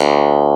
CLAV G1.wav